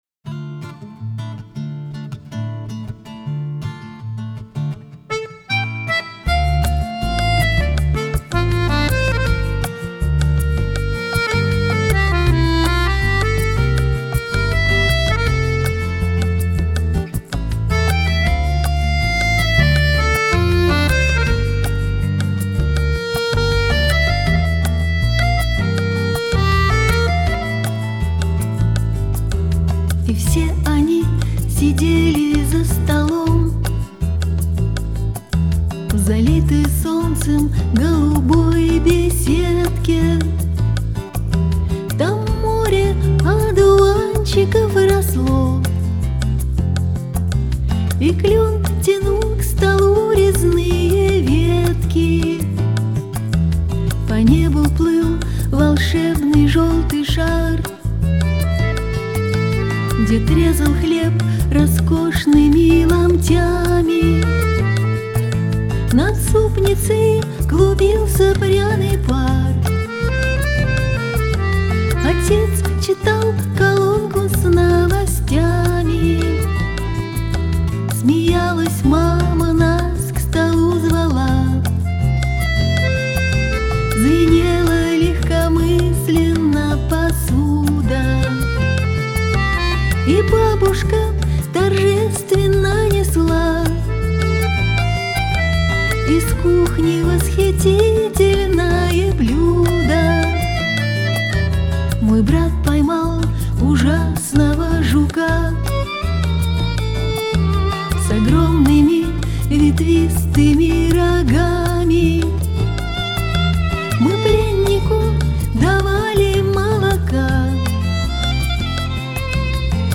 играющая в стиле "Сенти-Ментальный рок".
гитары, клавишные, перкуссия, сэмплы
скрипка
аккордеон